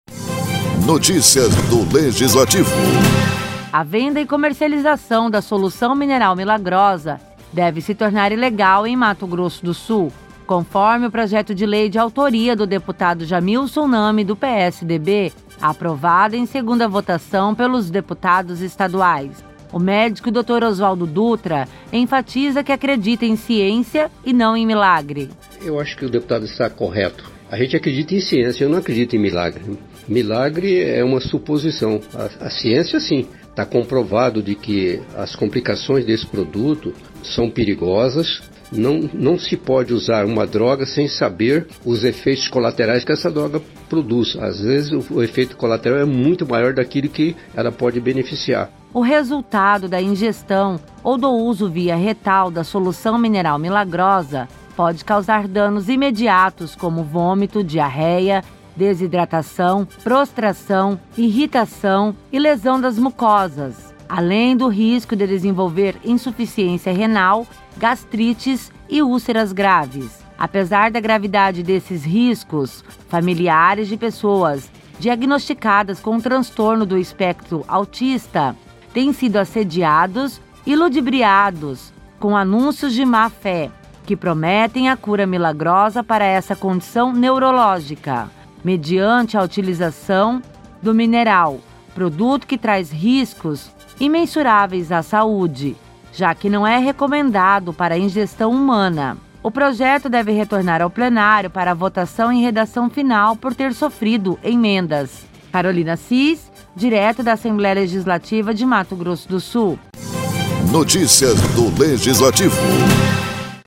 Produção e Locução: